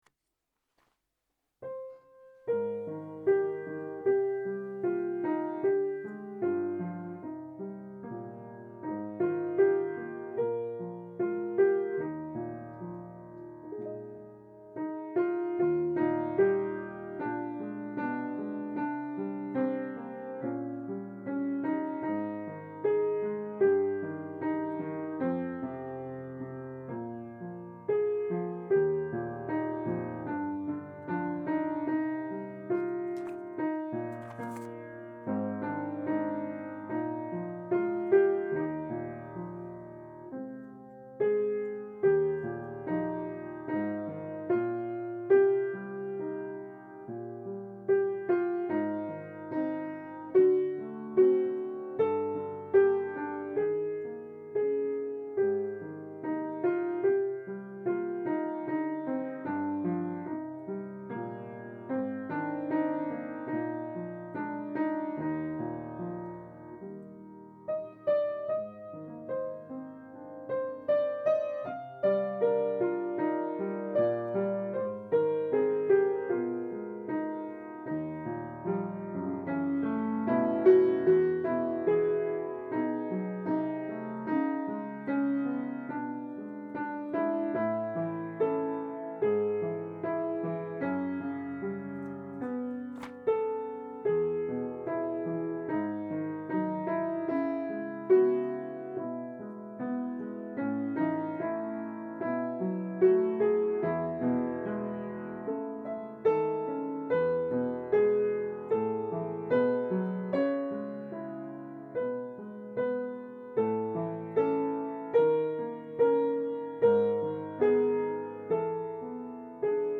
672  〈ここも神の〉アルトパート練習